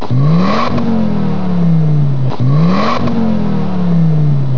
D      R       E       A       M       O       C       A       R       S      -     bruits de moteurs